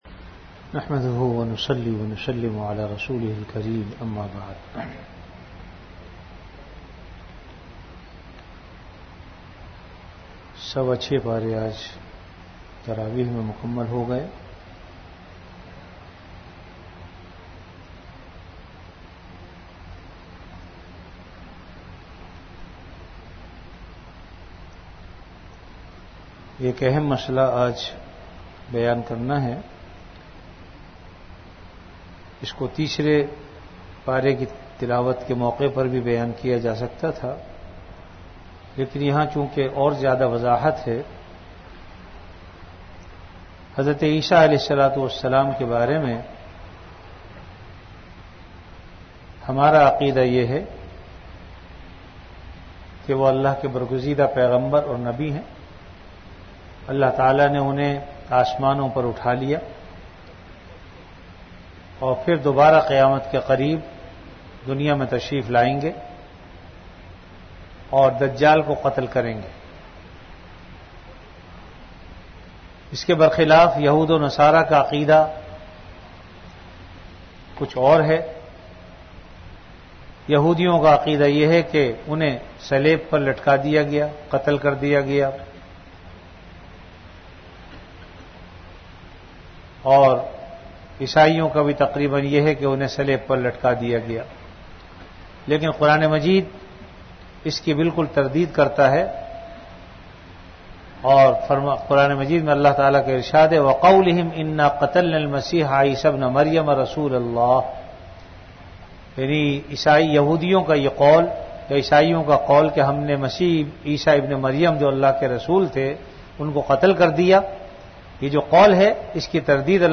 Ramadan - Taraweeh Bayan · Jamia Masjid Bait-ul-Mukkaram, Karachi